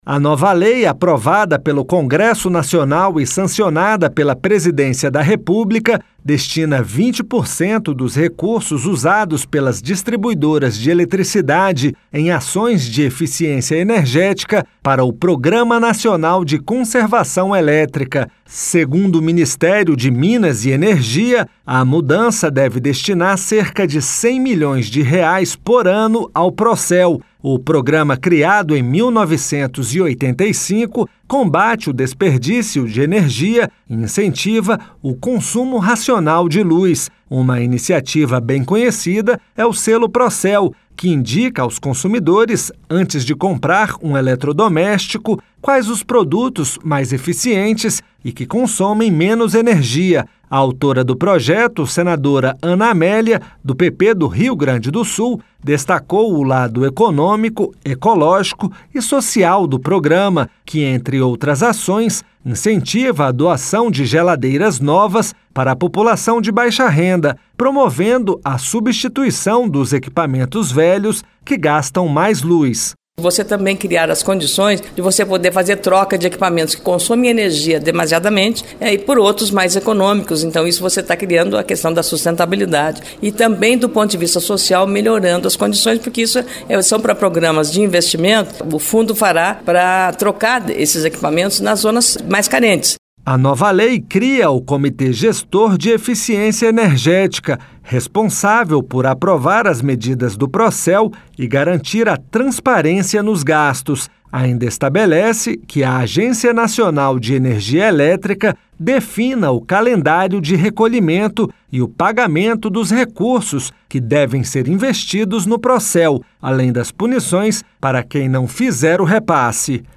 O repórter